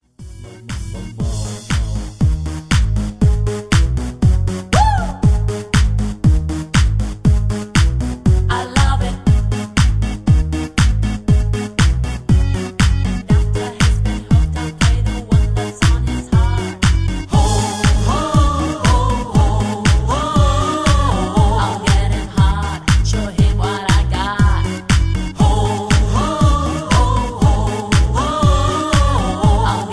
(Version-2, Key-Abm)
mp3 backing tracks